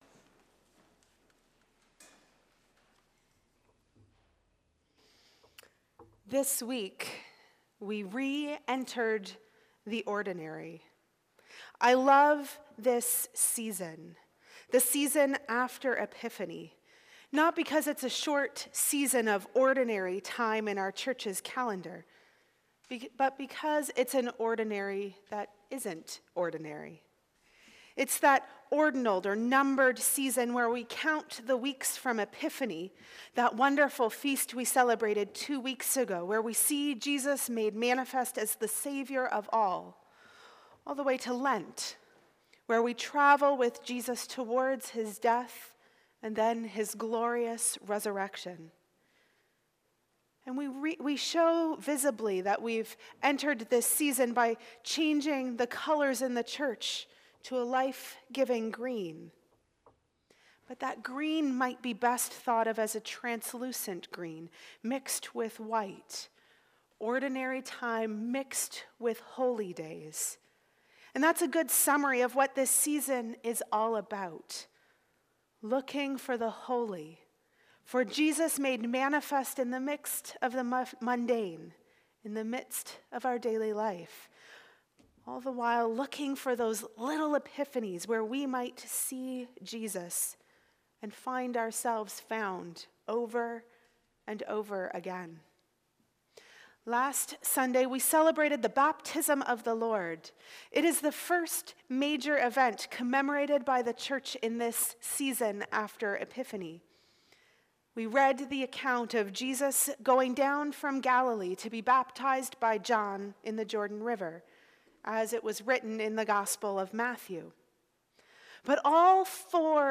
Sermons | Parish of the Valley
Recorded at Holy Trinity, Pembroke.